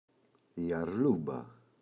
αρλούμπα, η [a’rlumba] – ΔΠΗ
αρλούμπα-η.mp3